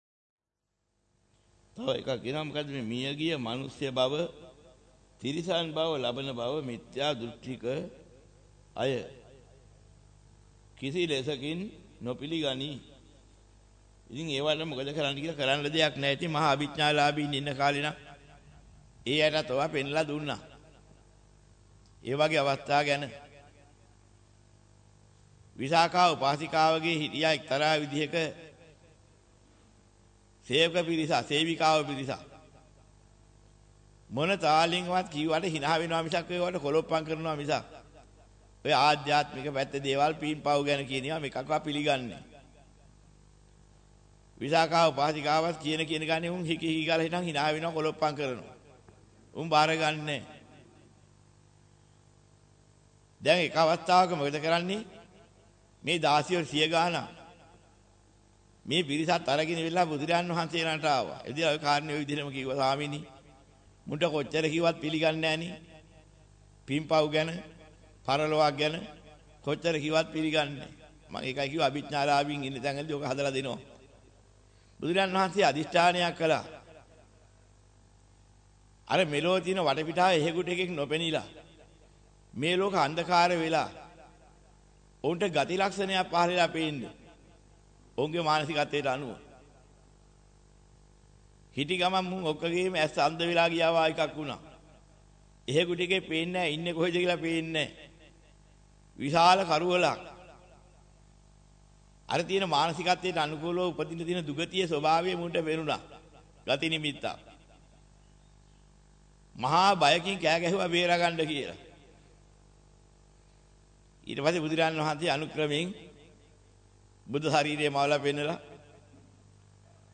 දහම් පිලි පිළිනොගන්නා අය ඔබගේ බ්‍රව්සරය පැරණියි. වෙනත් බ්‍රව්සරයක් භාවිතා කරන්නැයි යෝජනා කර සිටිමු 03:31 10 fast_rewind 10 fast_forward share බෙදාගන්න මෙම දේශනය පසුව සවන් දීමට අවැසි නම් මෙතැනින් බාගත කරන්න  (2 MB)